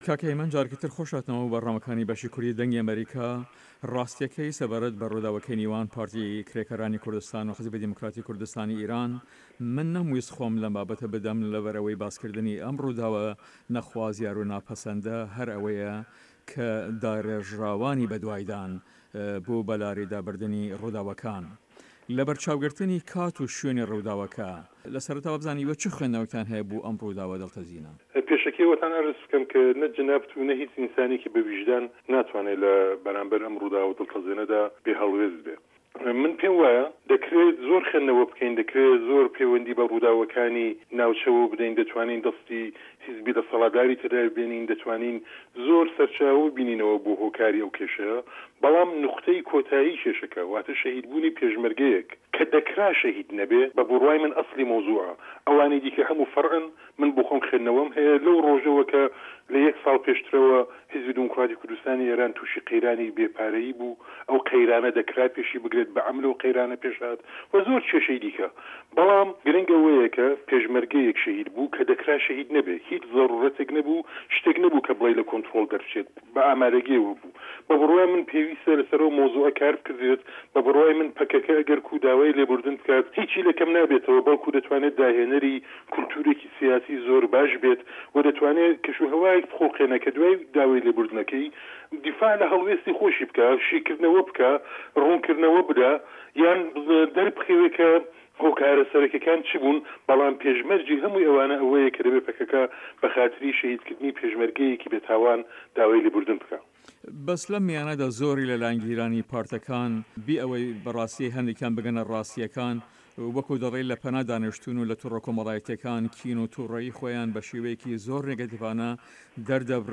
هه‌ڤپه‌یڤینێکدا